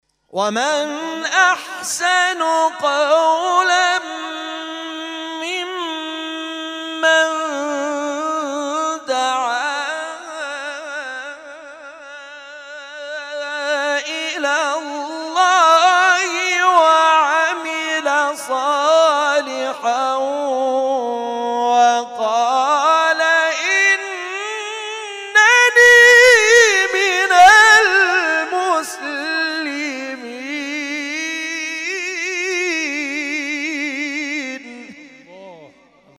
محفل انس با قرآن در آستان عبدالعظیم(ع)
قطعات تلاوت